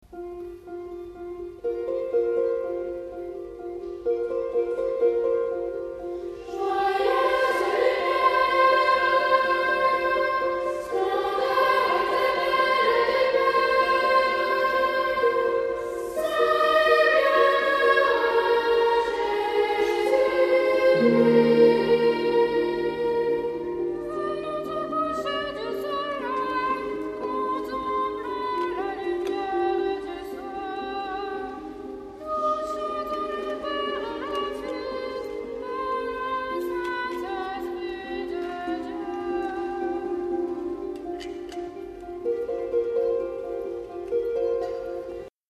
SSSSS (5 voix égale(s) d'enfants) ; Partition complète.
hymne (profane)
Caractère de la pièce : joyeux
Instrumentation : Harpe